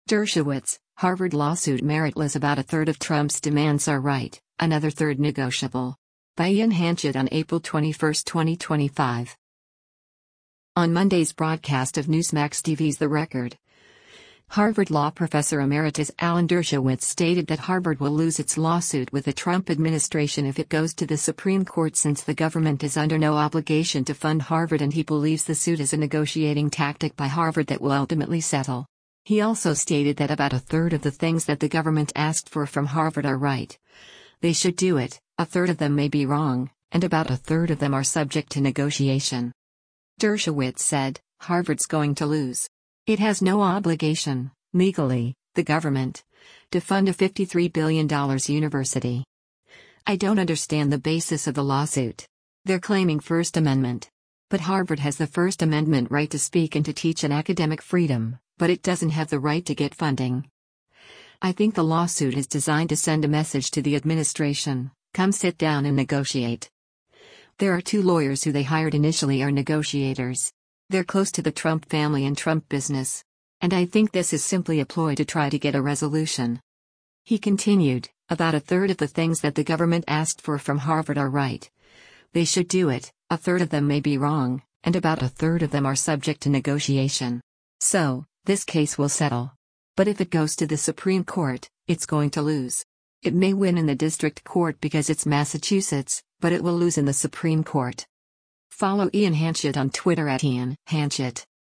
On Monday’s broadcast of Newsmax TV’s “The Record,” Harvard Law Professor Emeritus Alan Dershowitz stated that Harvard will lose its lawsuit with the Trump administration if it goes to the Supreme Court since the government is under no obligation to fund Harvard and he believes the suit is a negotiating tactic by Harvard that will ultimately settle.